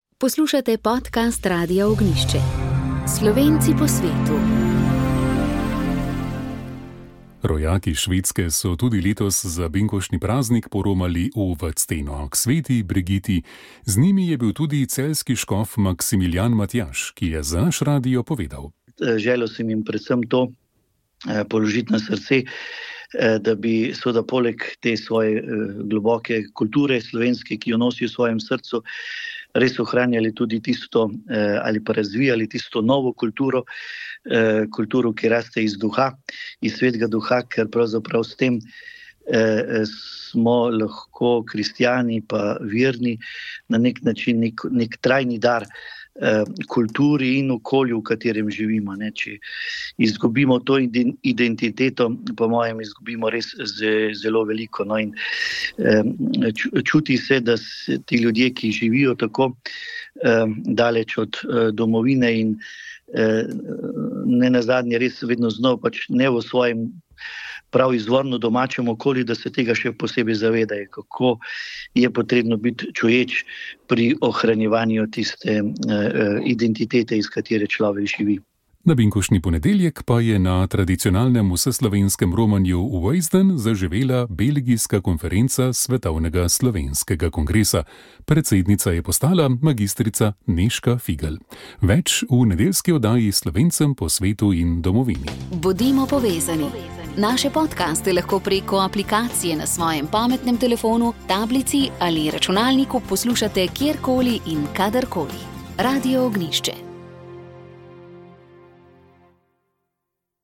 Slednji je za naš radio izrazil veliko zadovoljstvo …